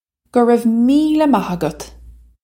Pronunciation for how to say
Guh rev mee-leh mah uggut!
This is an approximate phonetic pronunciation of the phrase.